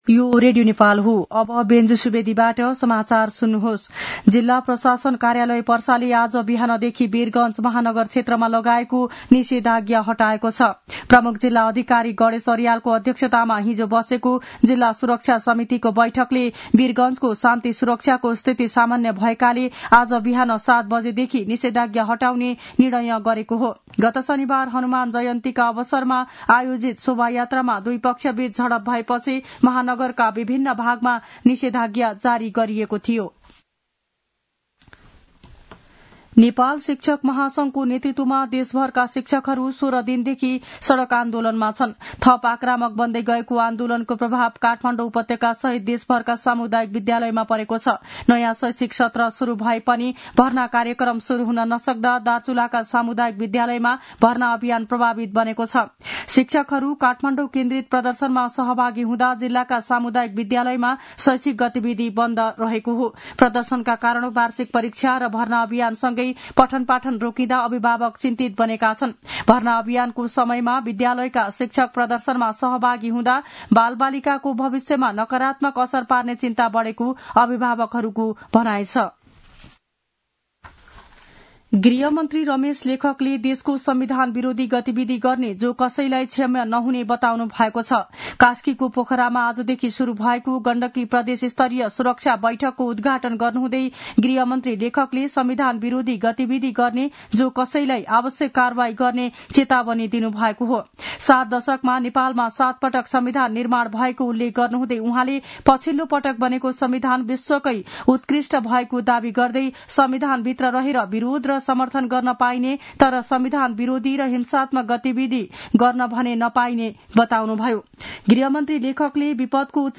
मध्यान्ह १२ बजेको नेपाली समाचार : ५ वैशाख , २०८२